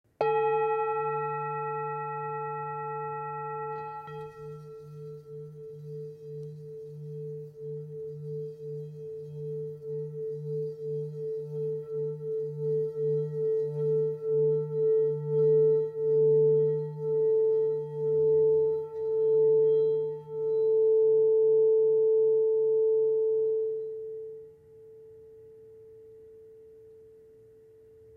Tibetská mísa Go velká
Tepaná tibetská mísa Go o hmotnosti 1671g.
Lahodné dlouhotrvající tóny tibetské mísy nám umožňují koncentrovat naši mysl, relaxovat a uvolnit naše tělo.
tibetska_misa_v18.mp3